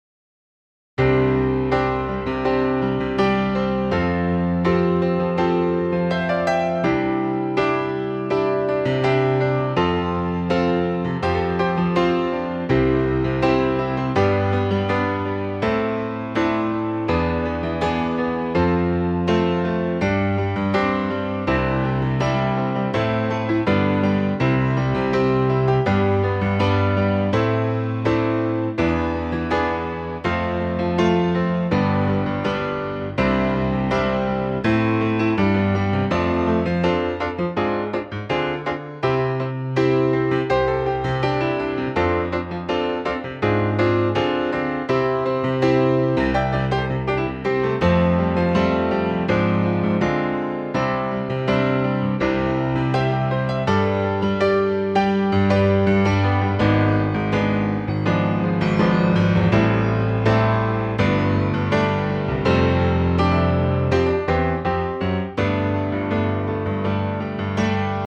key - C - vocal range - G to A
A punchy piano only arrangement